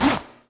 描述：拉链
Tag: 裤子 拉链 拉链